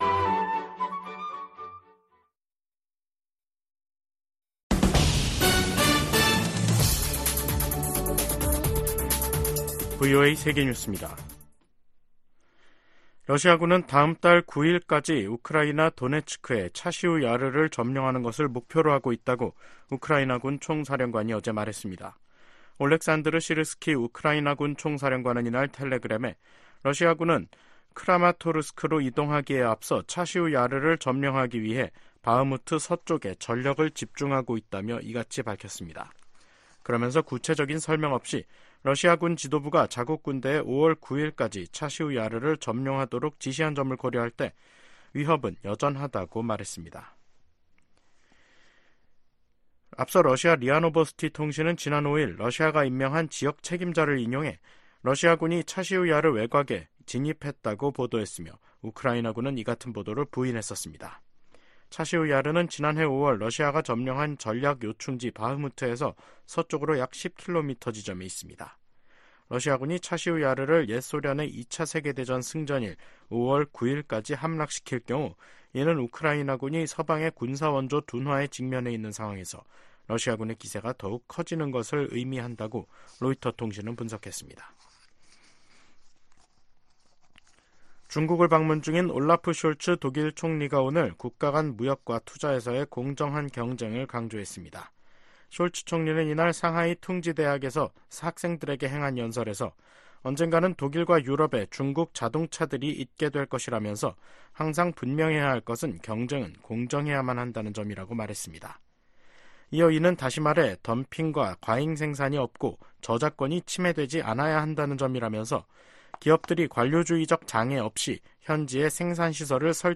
VOA 한국어 간판 뉴스 프로그램 '뉴스 투데이', 2024년 4월 15일 2부 방송입니다. 미국 유엔대표부 공사참사관은 러시아가 북한 무기 불법 조달을 은폐하기 위해 대북제재 전문가패널 임기 연장을 거부했다고 비판했습니다. 북한의 미사일과 발사 플랫폼 다각화로 미국과 동맹에 대한 위협이 가중되고 있다고 미 국방부 관리가 밝혔습니다.